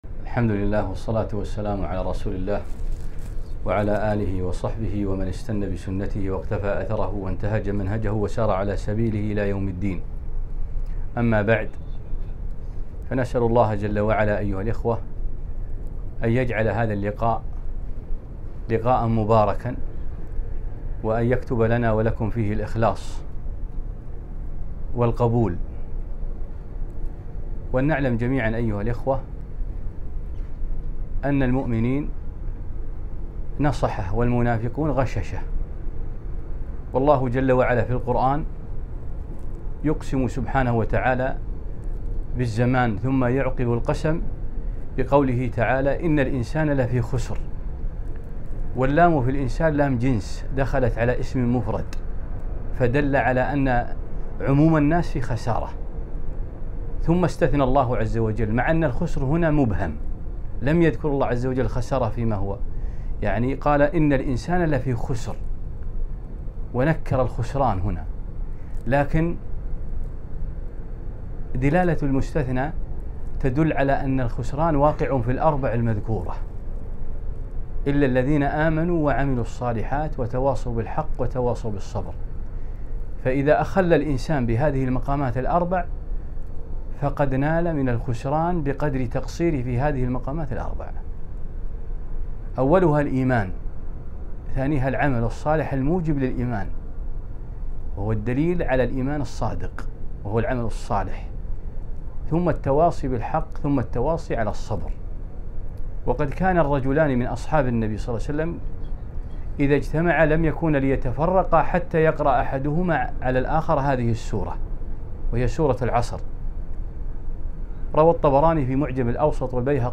محاضرة ماتعة - المخرج من الفتن